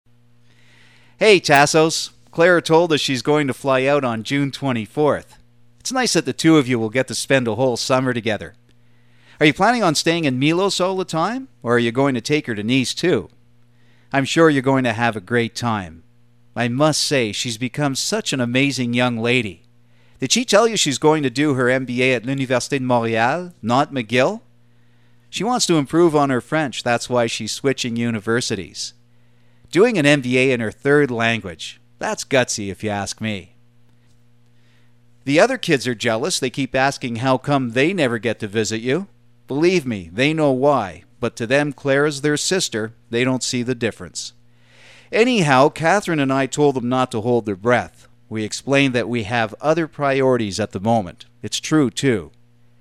Sprecher us-amerikanisch. 25 Jahre Radioarbeit: Nachrichten, Sport etc. -
middle west
Sprechprobe: eLearning (Muttersprache):